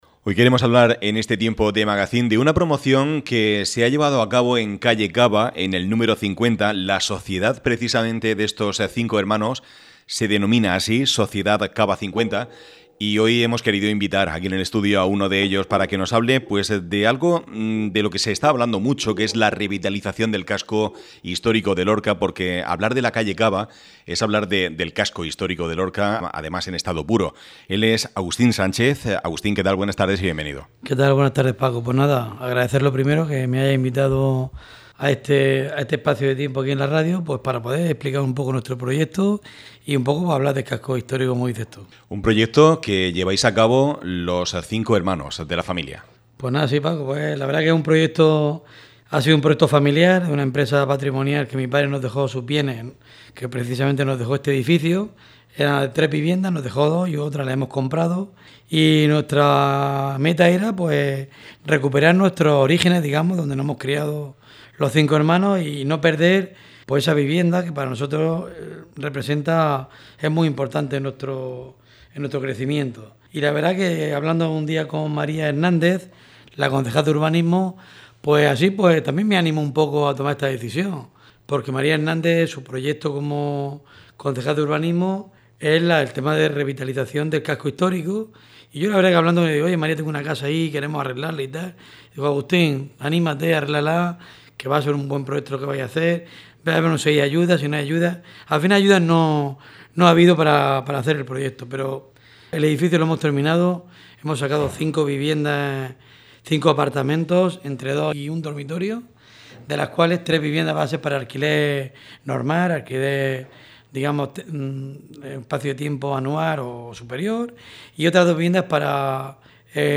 intervención en el magazine de Área Lorca Radio